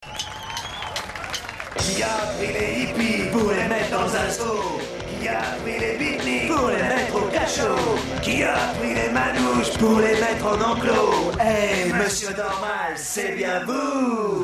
Vocal et guitares
Guitare
Basse
Batterie